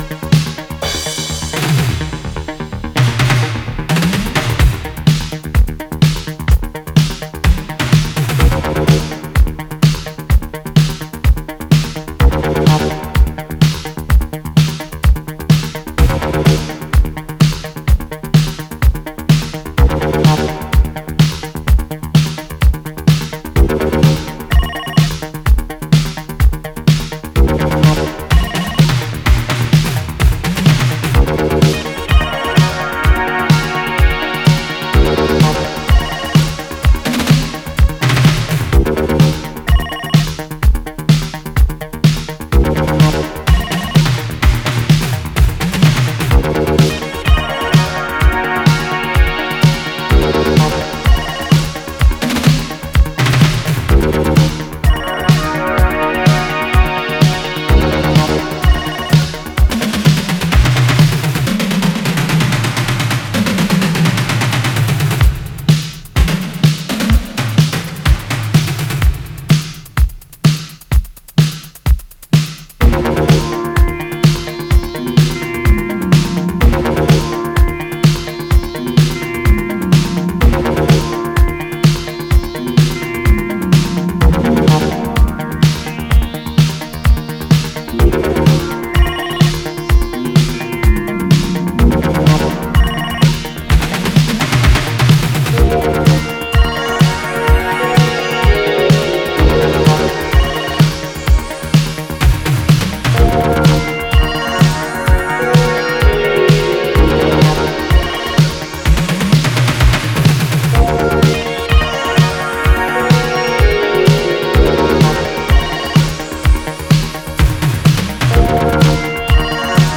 Four groovers of Cosmic, Disco and Italo adventures for all.